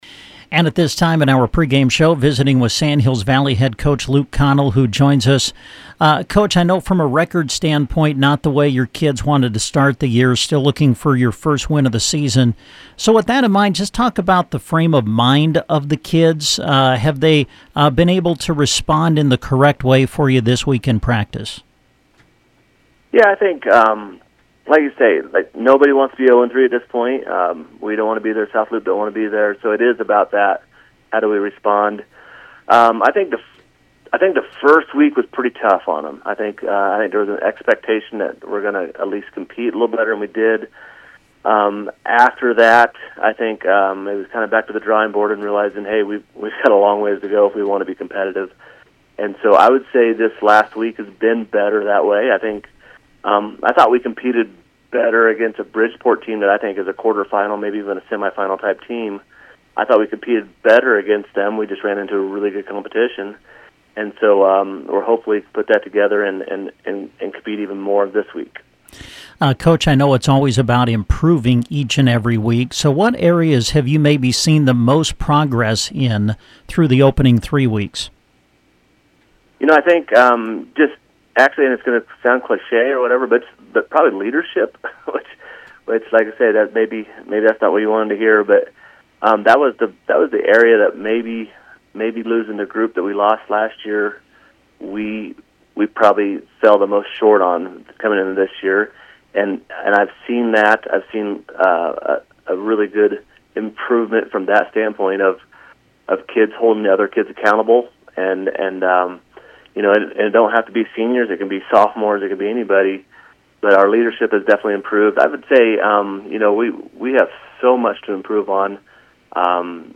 The interviews are posted below.